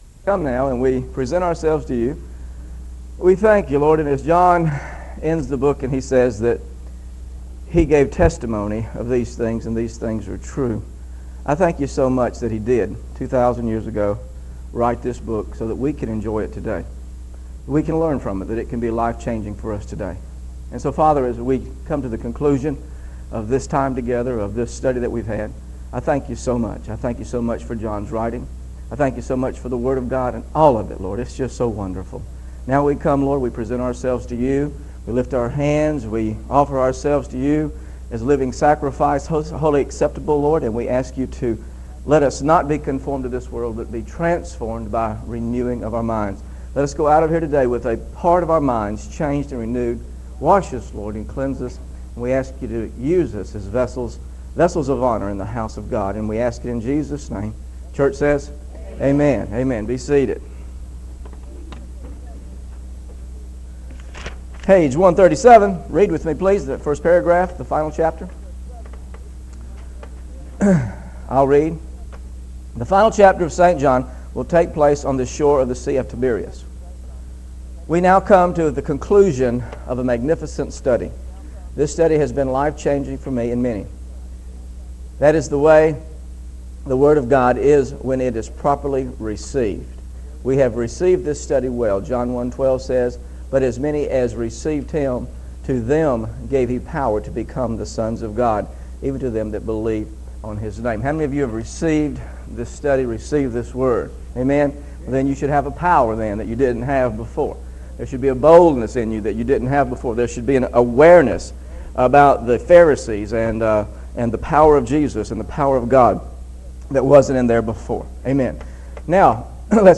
Scriptures used in this lesson